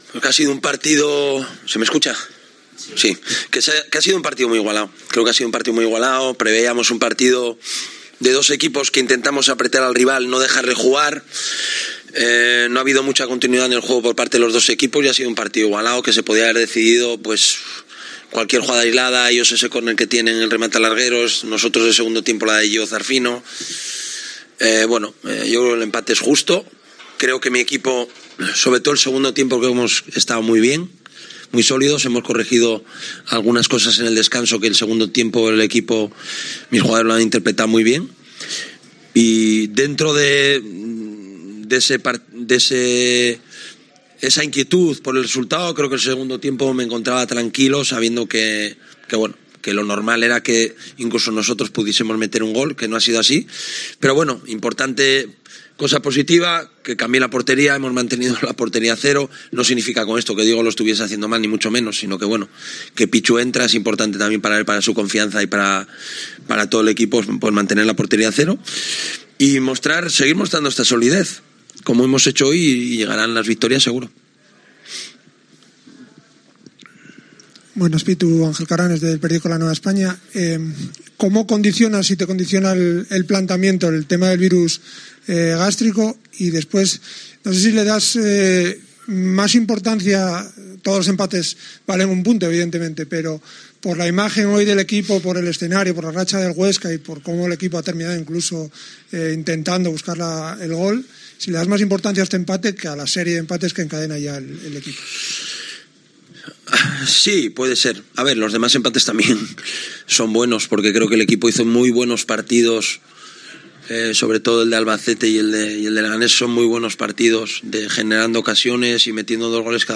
Rueda de prensa Abelardo (post Huesca)